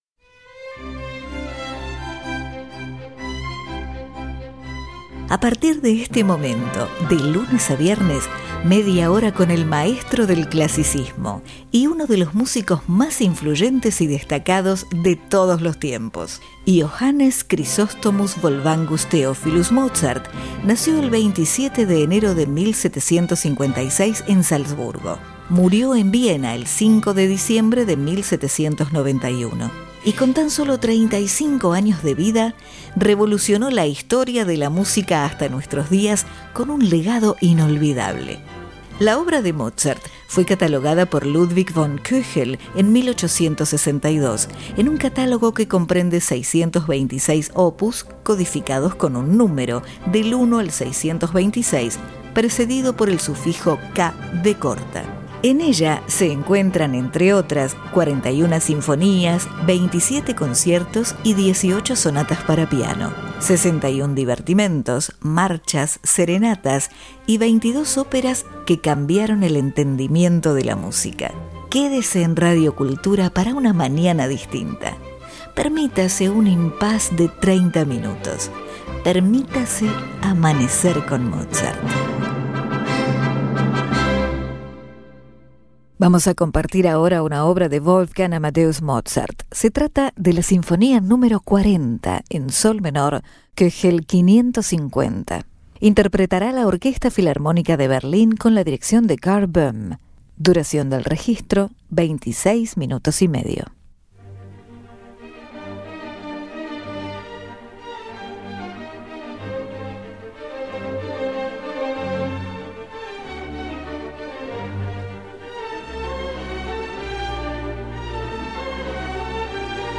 Sinfonia Nº 40 En Sol Menor K. 550 Orquesta Filarmonica De Berlin Director: Karl Böhm